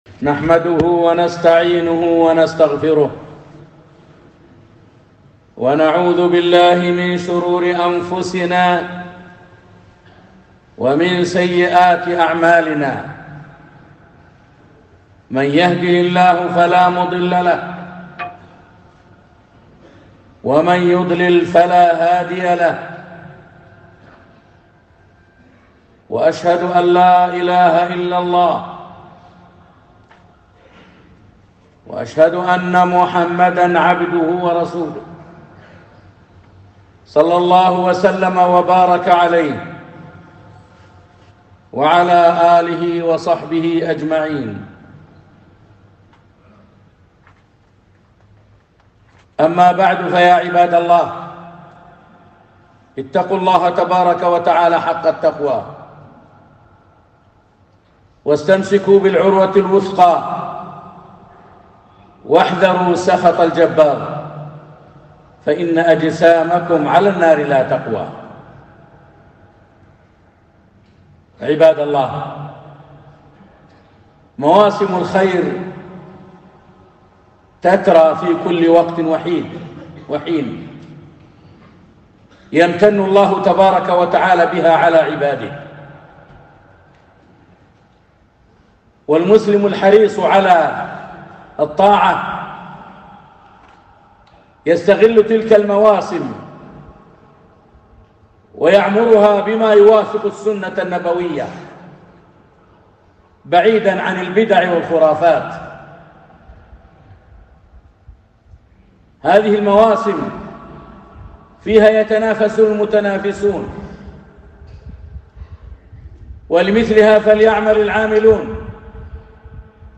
خطبة - هدي النبي ﷺ في شهر شعبان